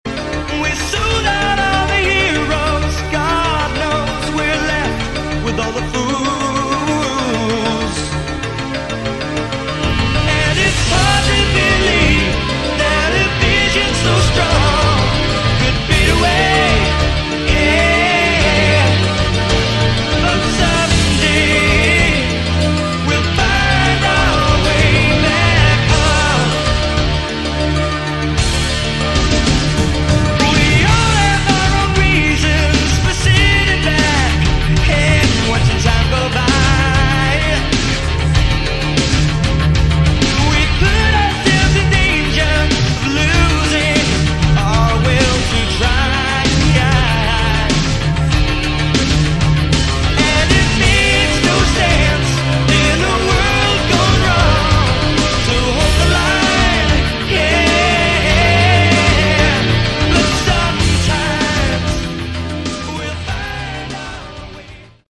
Category: Melodic Rock
Second CD consists of unreleased demos.